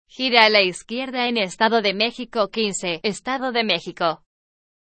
Entidad Federativa Prefijo Ejemplo TTS (Ariane)